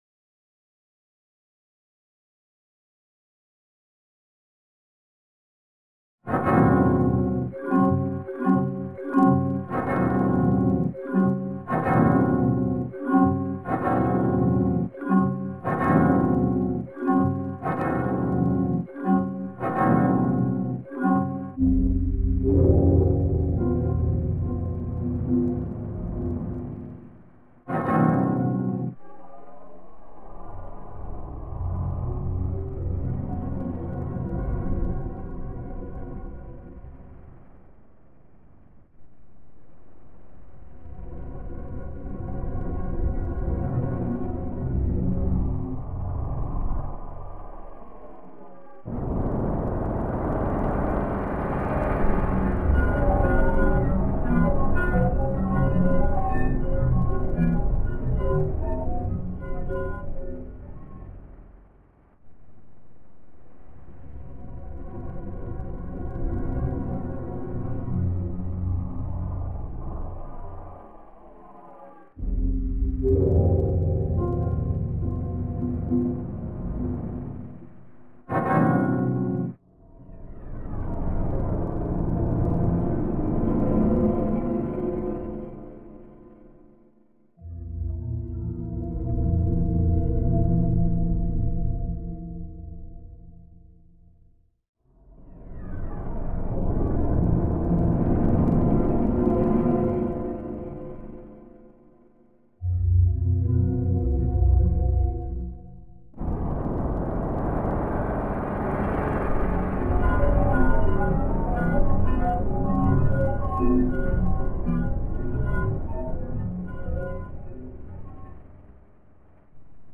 Windows Startup and Shutdown Sounds Updated in Sponge Effect.mp3